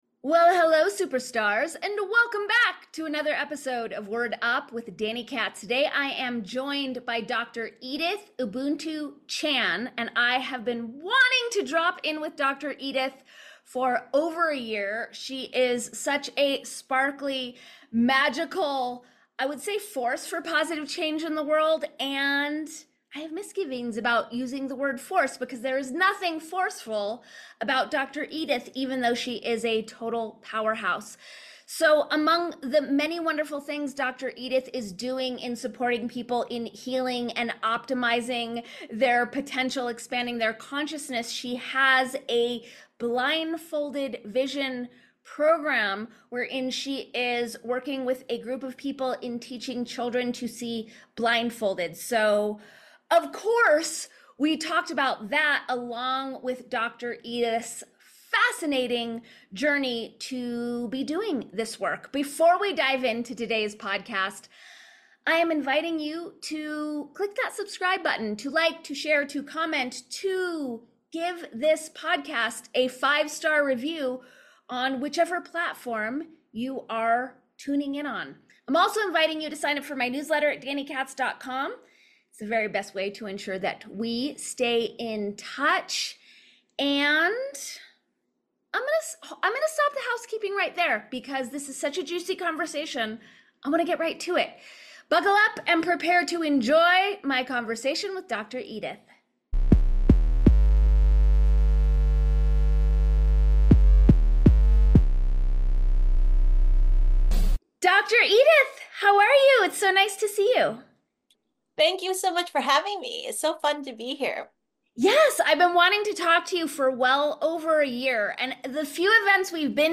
A solo pod romp through the myriad ways we are programming ourselves and others, and being programmed with weaponized (or simply unconscious) languaging habits. Education is power, superstar, which is why I highly, strongly and enthusiastically recommend learning how to safeguard your sovereignty and agency with your every word!